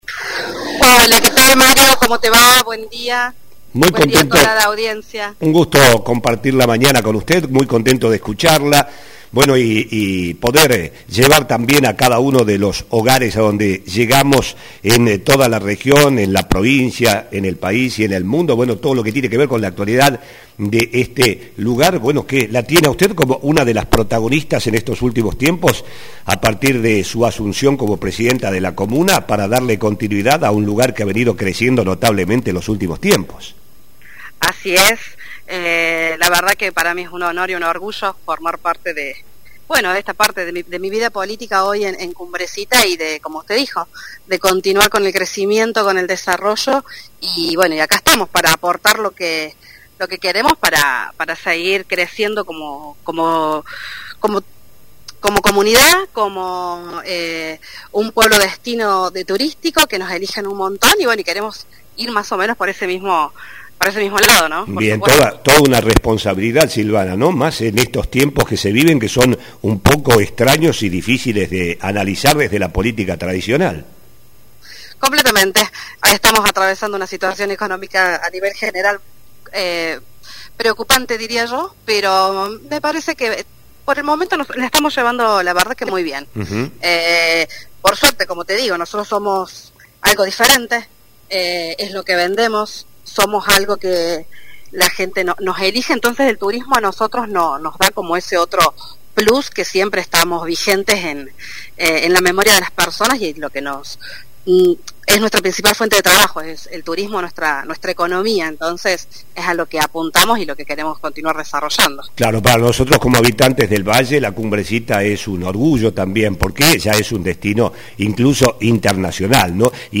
Desde el 9 de mayo y hasta el 12 se desarrollará la competencia Varholl Argentina by UTMB que reunirá a competidores de 45 países y que clasificará a los ganadores para correr en Francia. Dialogamos sobre éste y otros temas de actualidad con la presidenta Comunal Silvana Torres.